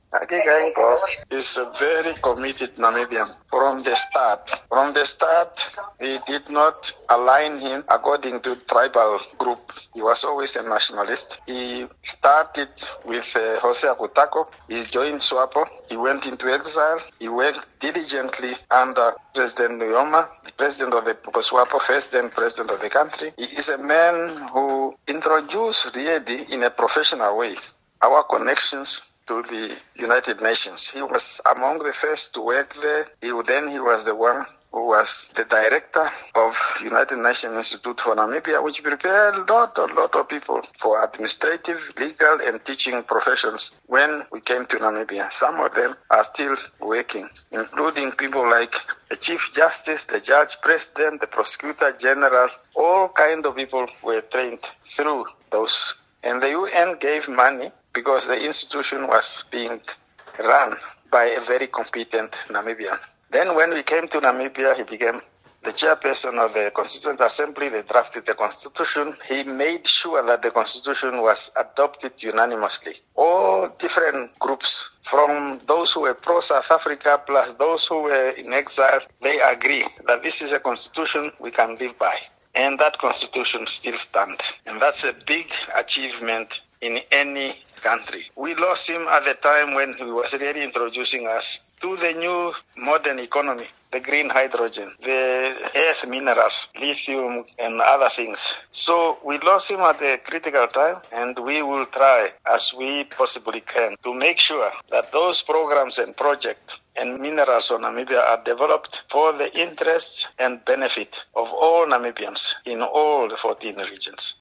5 Feb Dr Nangolo Mbumba interview after his inauguration.